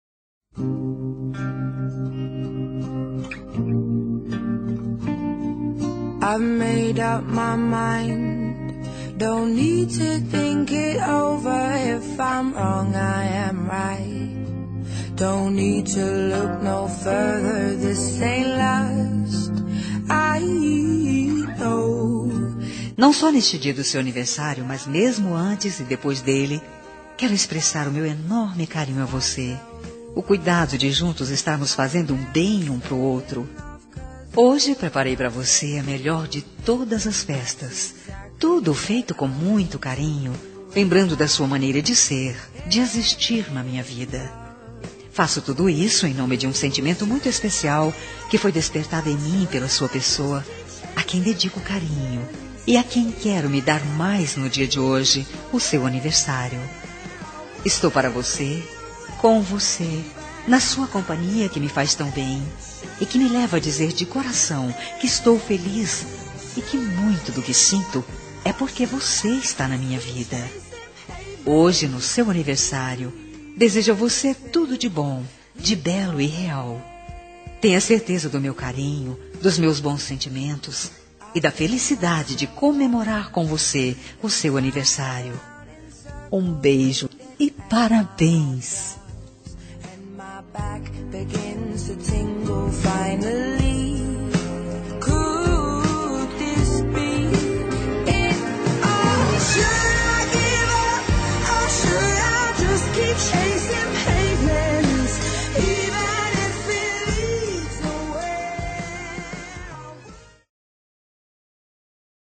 Telemensagem Aniversário de Paquera -Voz Feminina – Cód: 1244